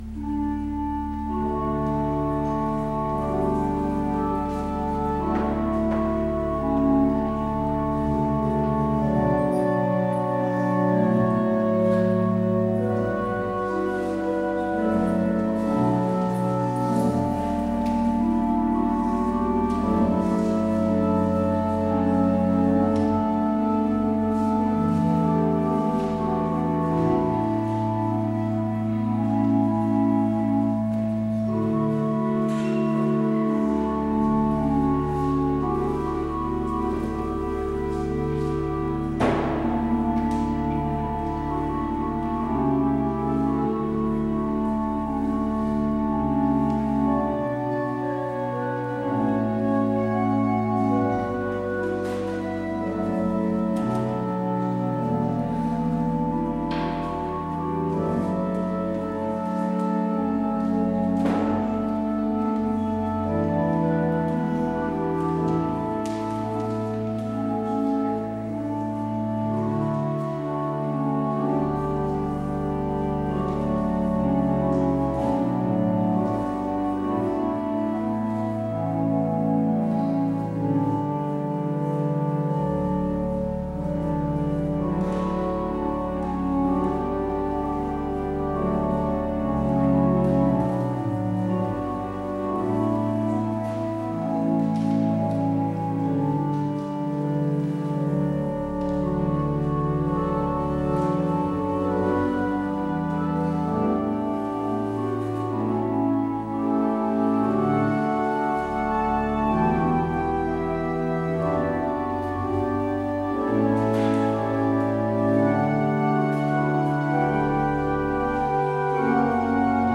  Luister deze kerkdienst terug
Als openingslied Psalm 117 (Loof, alle volken, loof de Heer). Het slotlied is Lied 442: 1,2 (Op U, mijn Heiland, blijf ik hopen).